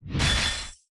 120-Ice01.opus